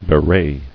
[be·wray]